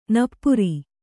♪ nappuri